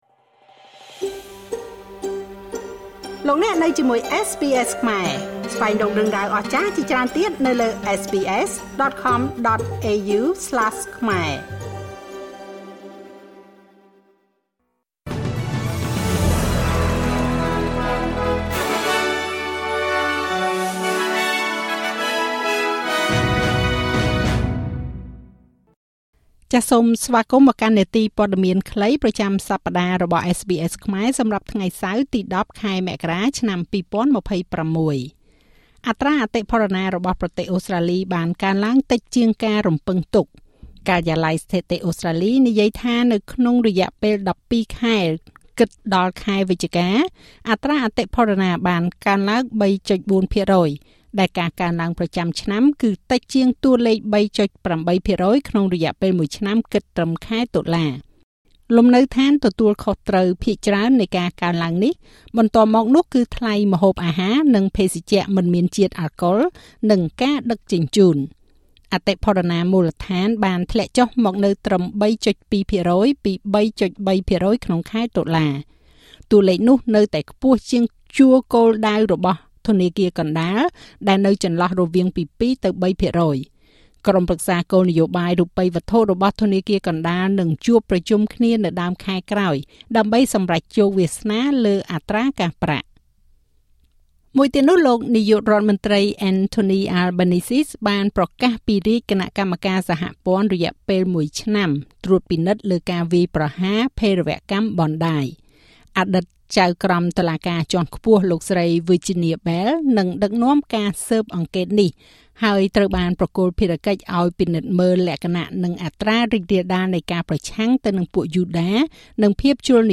នាទីព័ត៌មានខ្លីប្រចាំសប្តាហ៍របស់SBSខ្មែរ សម្រាប់ថ្ងៃសៅរ៍ ទី១០ ខែមករា ឆ្នាំ២០២៦